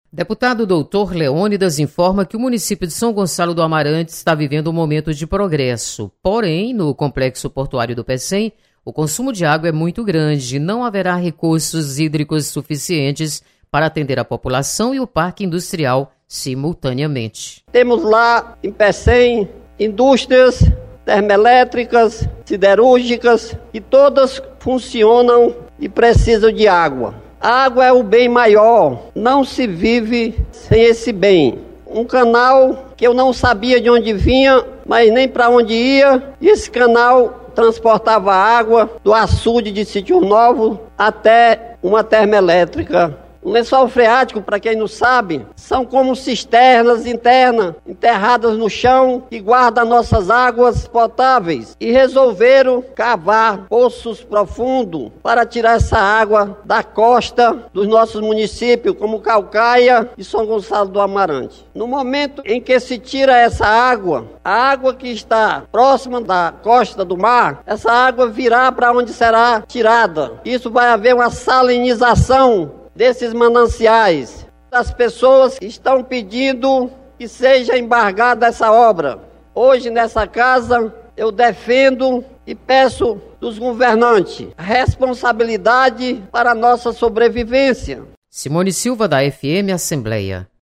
Deputado Doutor Leônidas destaca progresso de São Gonçalo do Amarante. Repórter